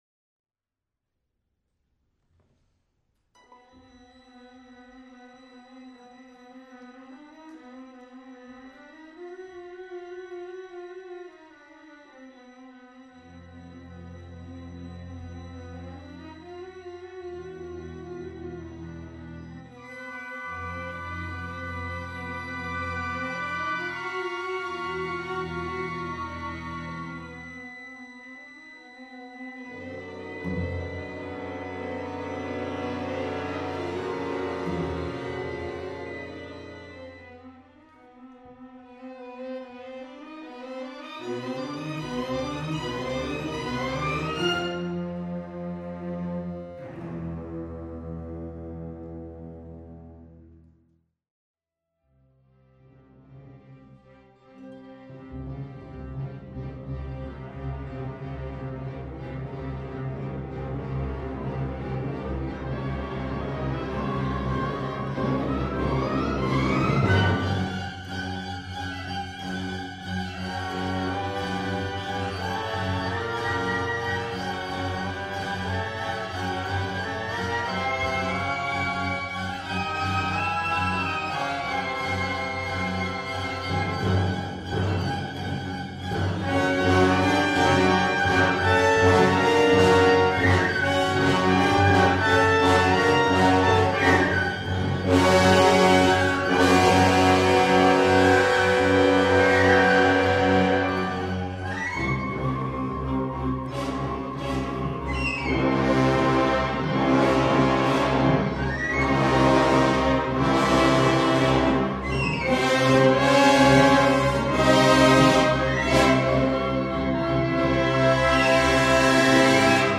[GASP] = Great Audio Sneak Preview from rehearsal on April 16, 2007 - 4 more rehearsals... (:-)
Prelude_rehearsal_clip.mp3